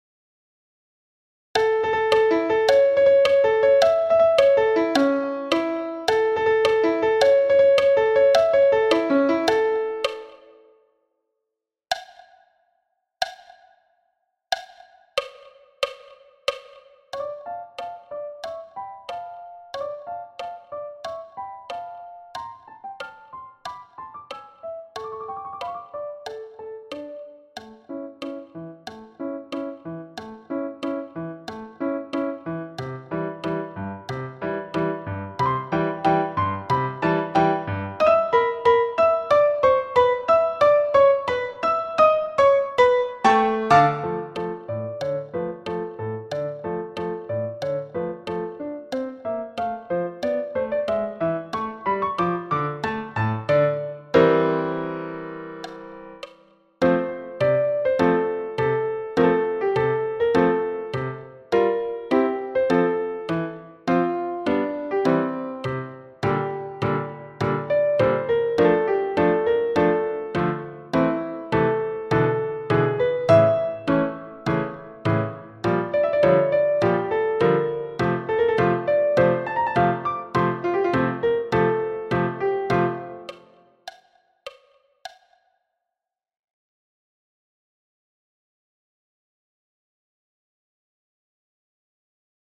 Xylo-2-piano-a-92-bpm
Xylo-2-piano-a-92-bpm.mp3